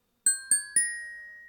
Hovering_hawk.ogg